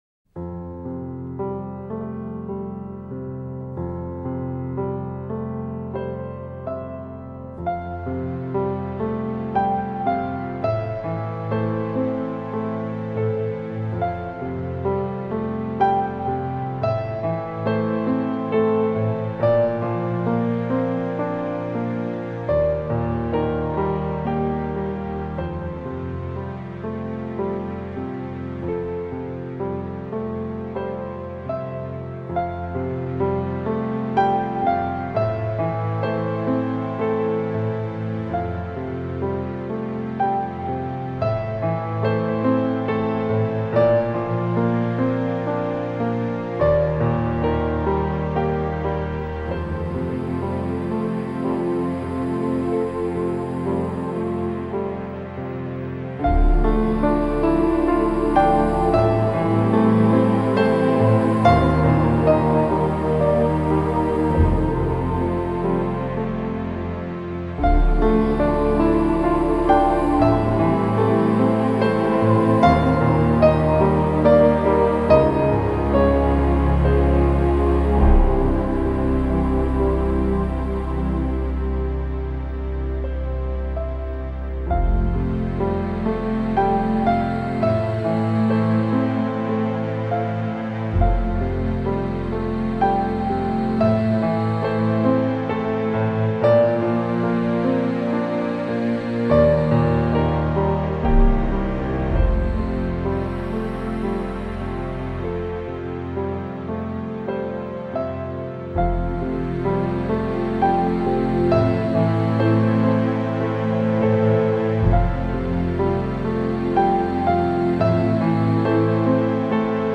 Gatunek: Rock.